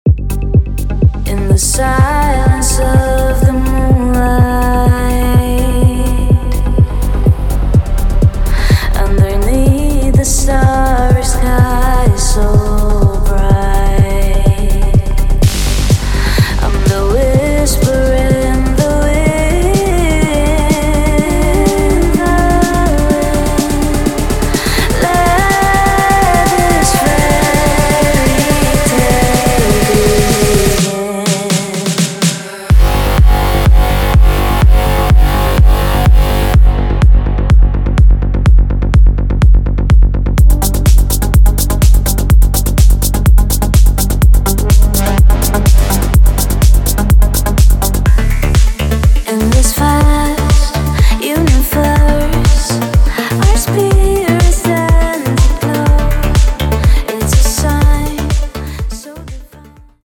Melodic Techno